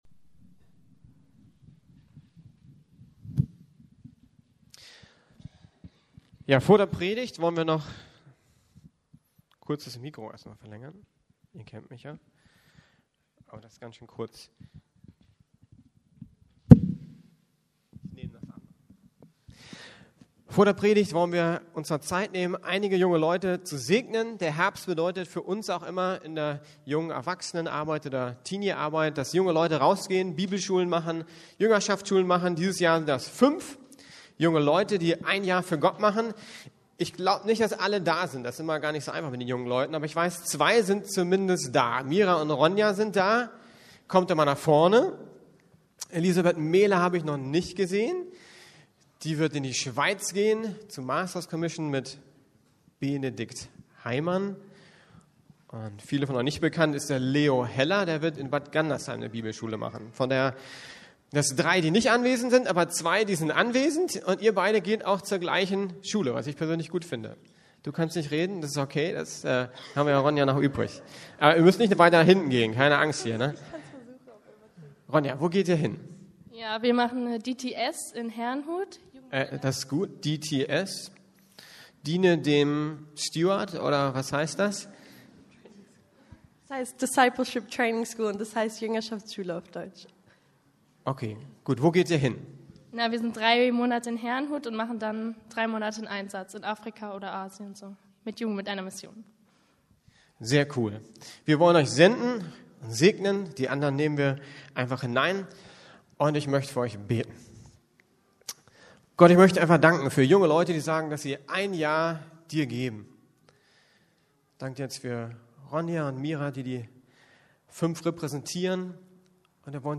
Eine Kultur der Ehre (Gnade) ~ Predigten der LUKAS GEMEINDE Podcast